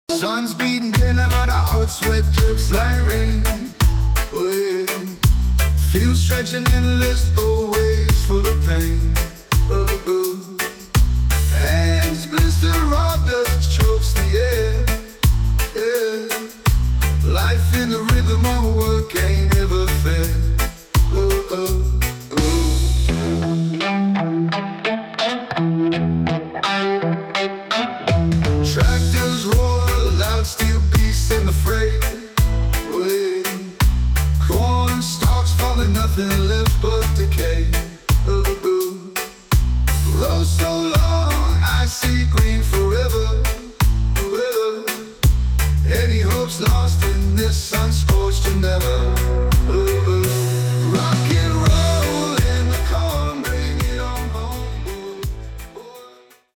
An incredible Hip Hop song, creative and inspiring.